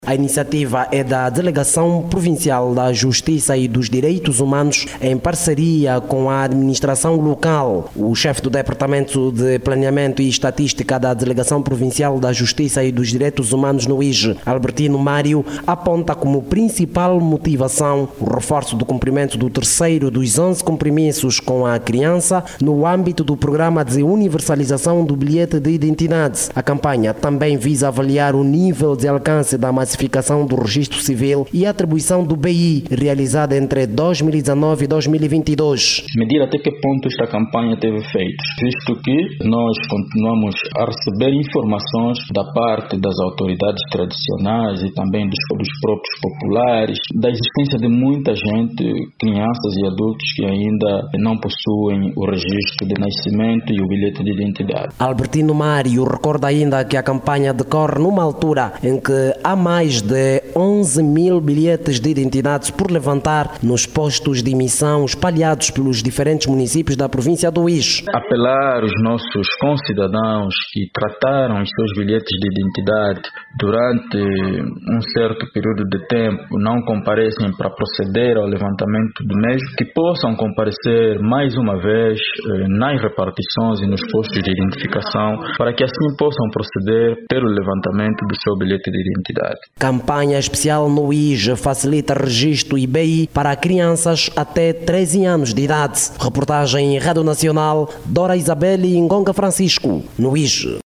Arranca hoje, quarta-feira(16), na província do Uíge a campanha especial de registo de nascimento e atribuição de Bilhete de Identidade. Para esta campanha, são esperados mais de duzentos menores dos zero aos 13 anos de idade. Clique no áudio abaixo e ouça a reportagem